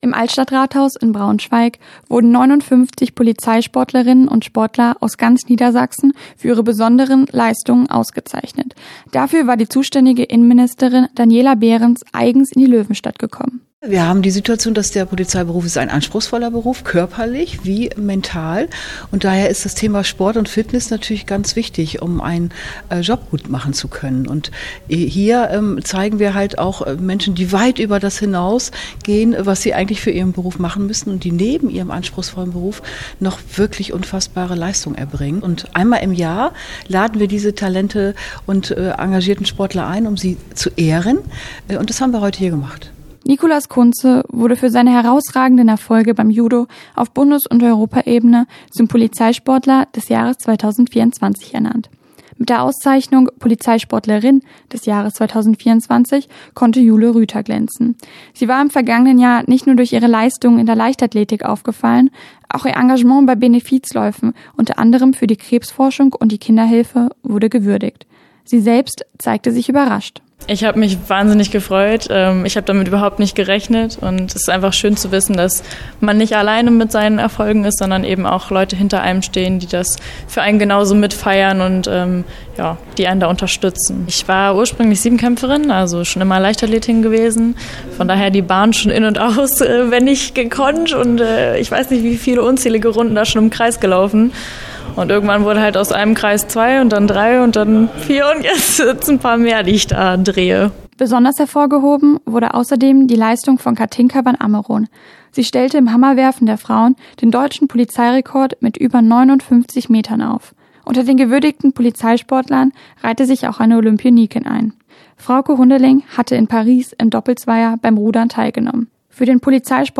Festakt im Braunschweiger Altstadt-Rathaus: Innenministerin Behrens zeichnet erfolgreiche Polizeisportler aus - Okerwelle 104.6